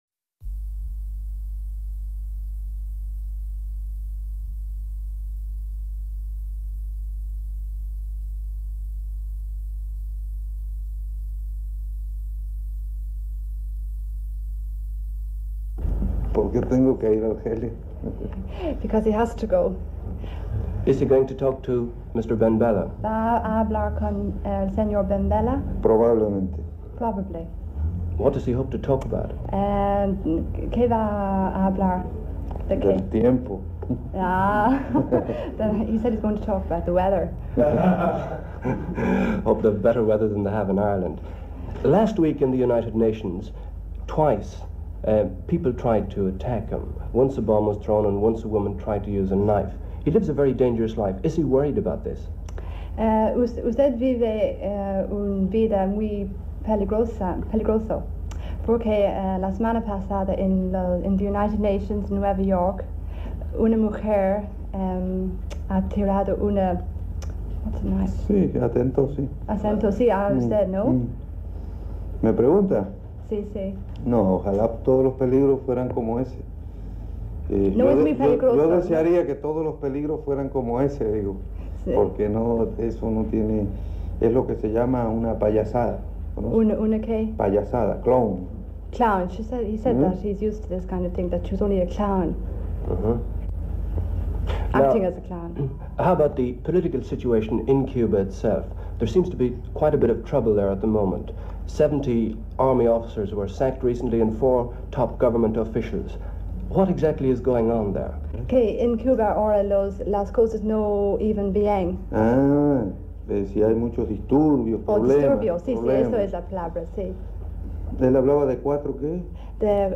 Argentine born Cuban revolutionary Che Guevara on a short visit to Dublin. Argentine born Major Ernesto Che Guevara, and ‘Comandante’ of the Cuban Revolution, was interviewed by RTÉ at Dublin Airport when as Cuban Minister for Industries he was travelling on a diplomatic mission.
An RTÉ News report broadcast on 18 December 1964.